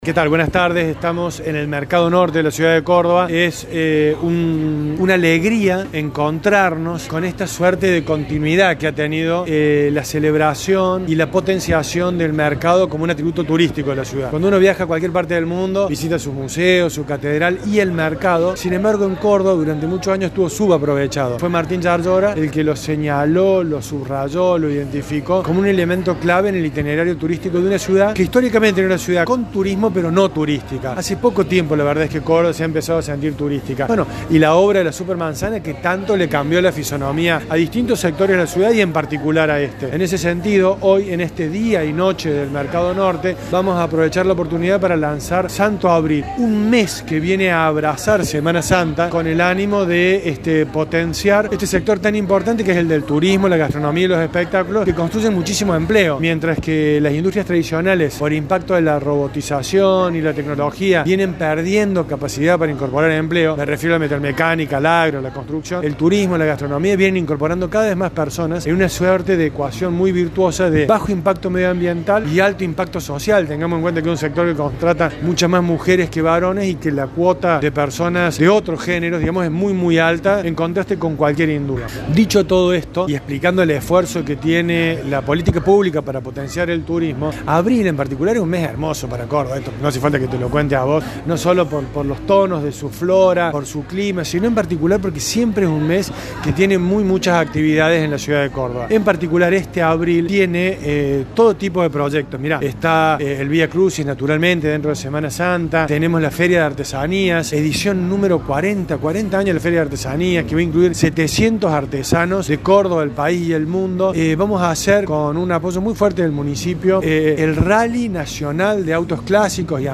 Francisco “Pancho” Marchiaro, director de Promoción de la Ciudad de Córdoba, fue entrevistado por Villa María VIVO sobre las actividades que la capital provincial tiene agendadas para el mes de abril y habló sobre el cambio de concepto en materia turística que vive la principal urbe del interior del país.